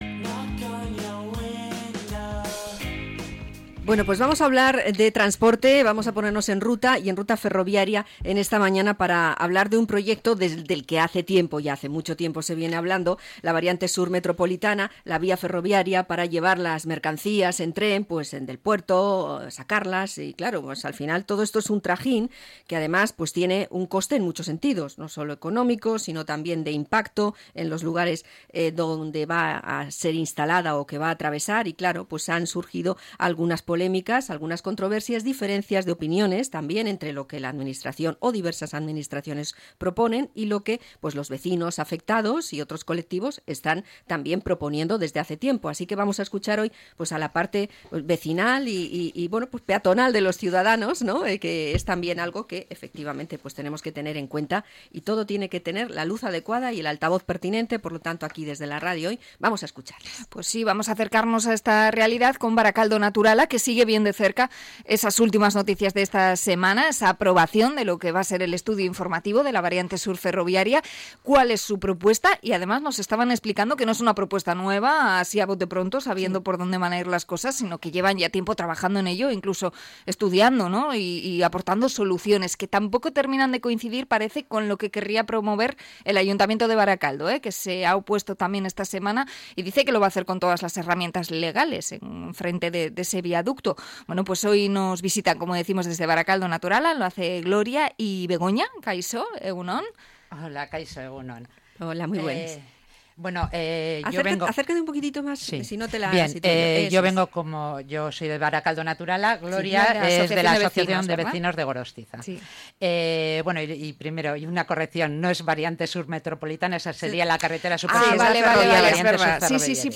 Entrevista a Barakaldo Naturala por la Variante Sur Ferroviaria